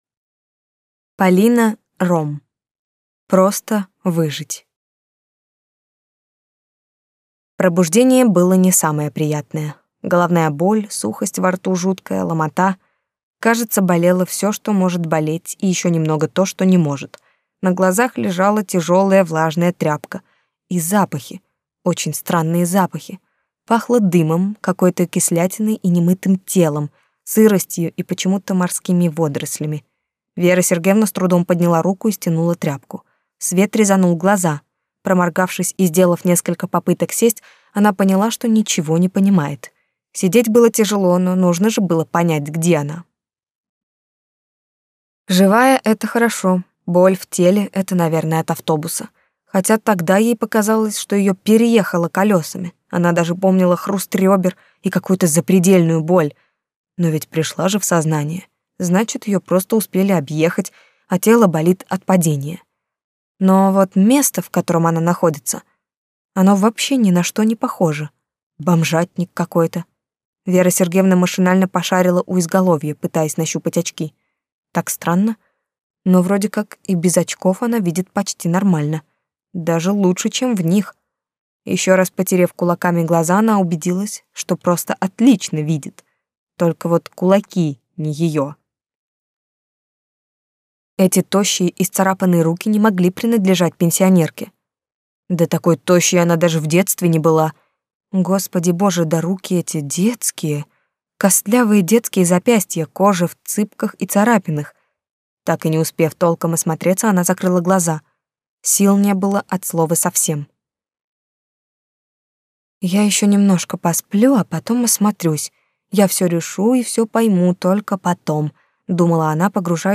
Аудиокнига Просто выжить | Библиотека аудиокниг